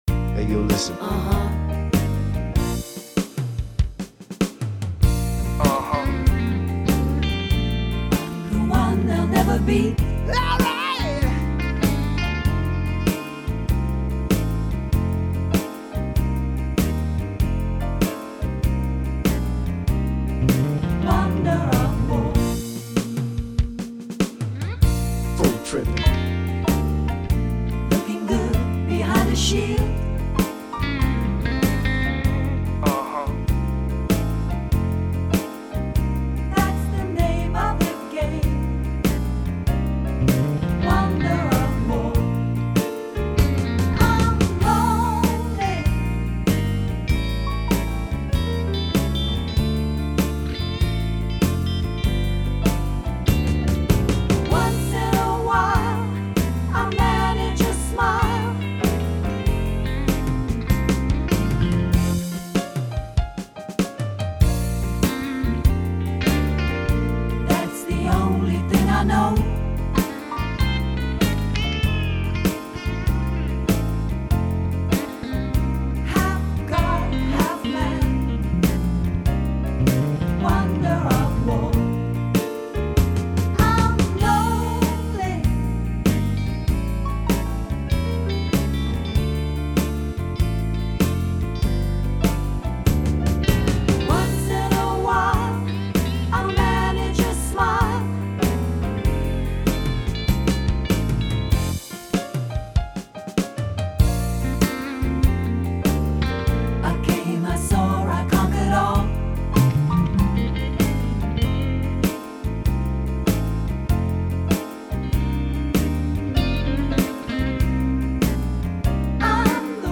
Song style: pop
Download the part vocal (harmony) version of the song